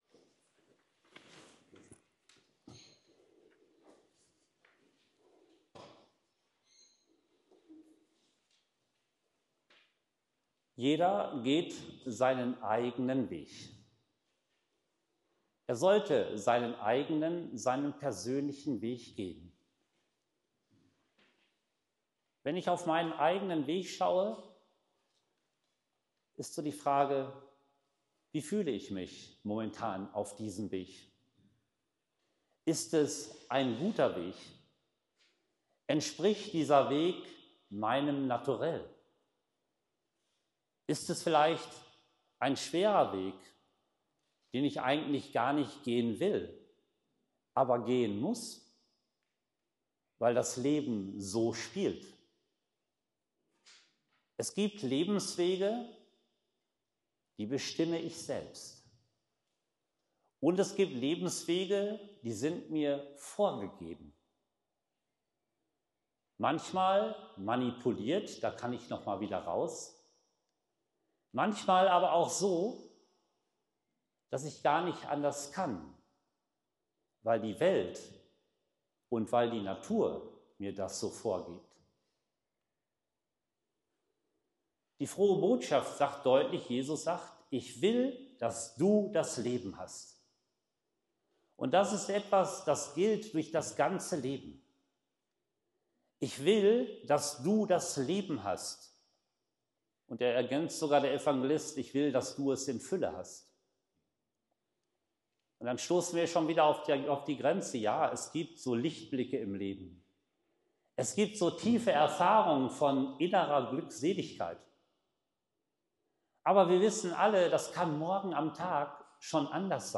Predigt-Audio.mp3